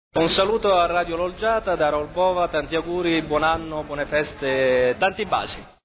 Gli Auguri di Natale